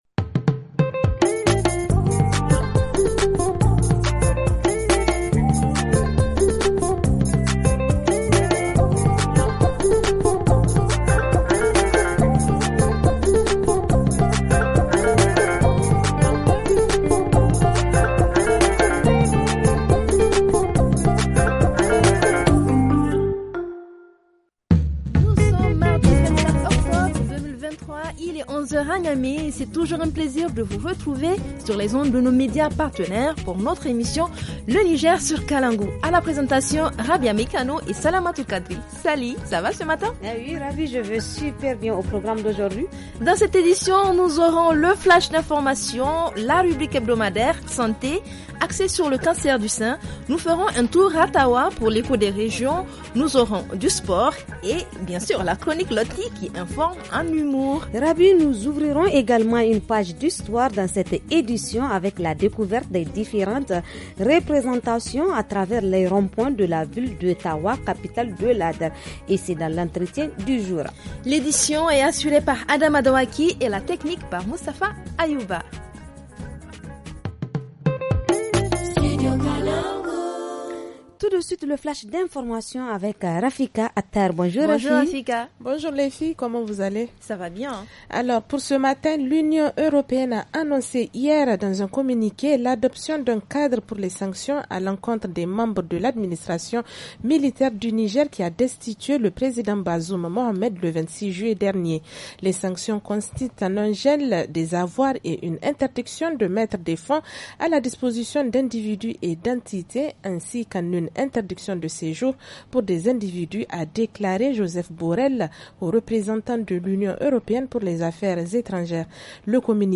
– Reportage Région : A Birni N’konni, des personnes sont fréquemment enlevées ; -Playlist musique :